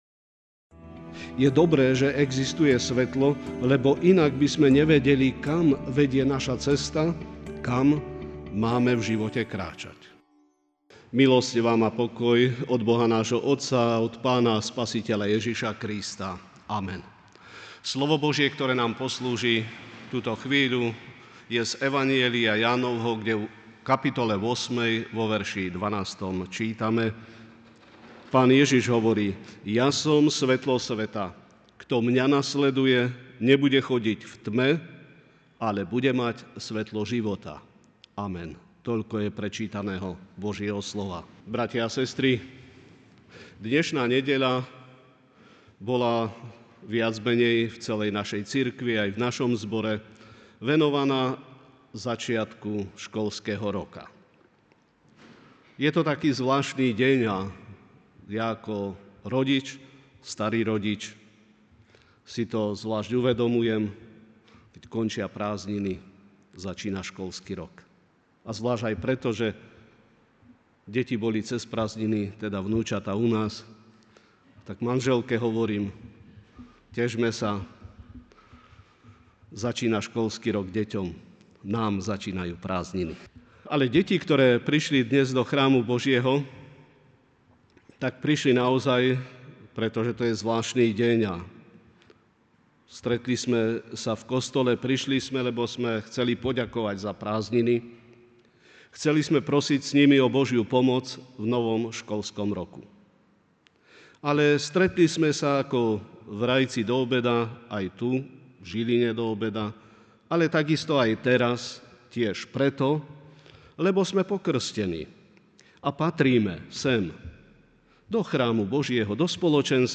Večerná kázeň: Cesta vo svetle ( Ján 8,12)